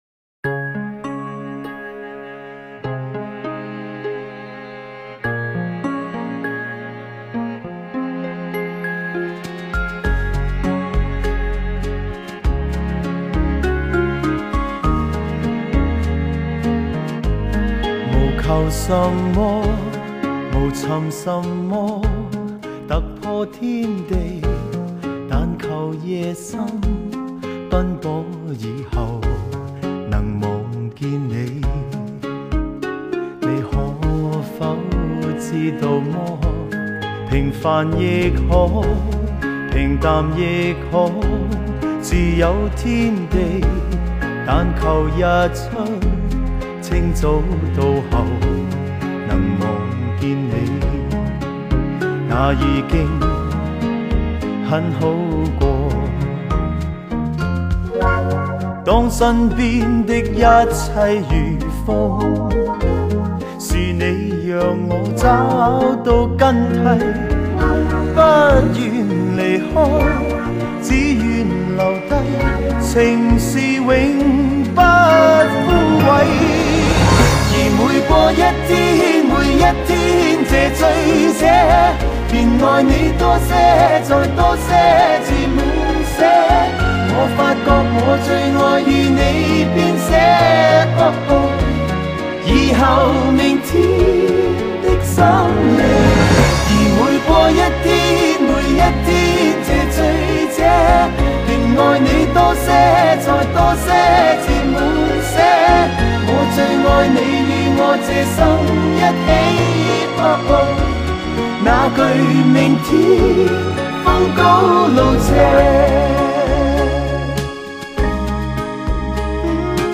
DSD 重新编制，音质保证！